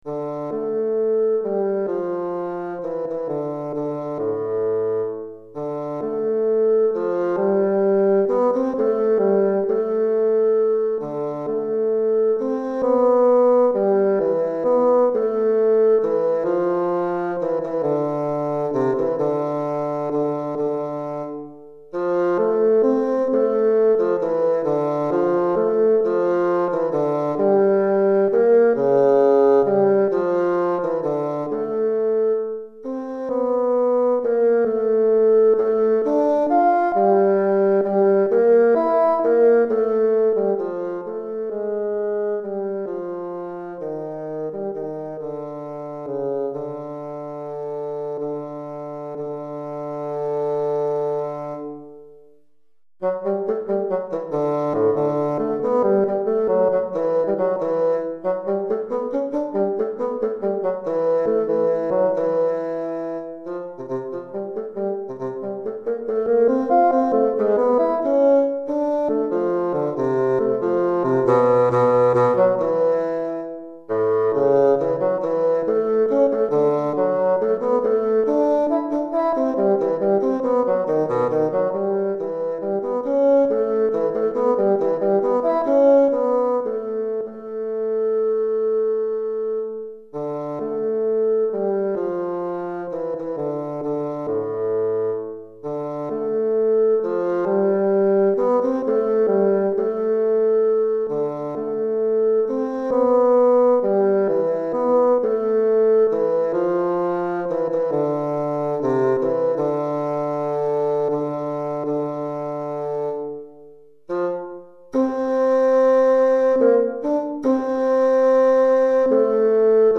Pour basson solo DEGRE FIN DE CYCLE 1
Basson solo